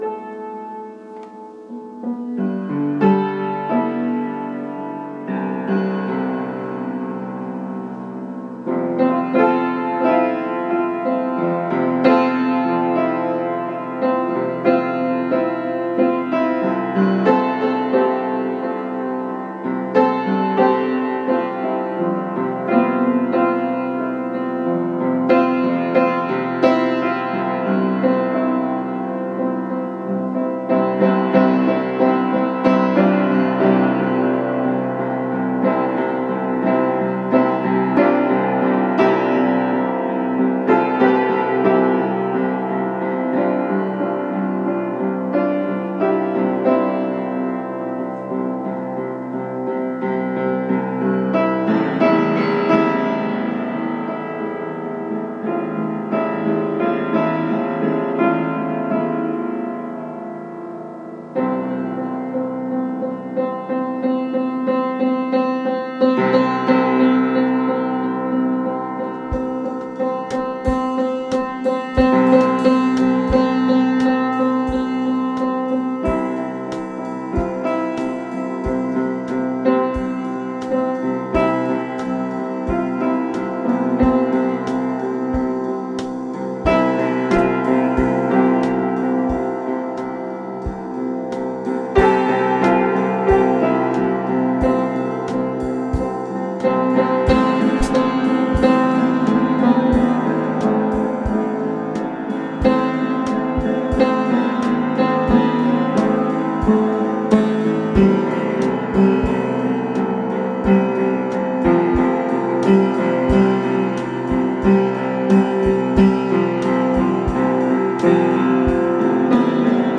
Piano with Drums
It was recorded then later decently miked drums were played over it.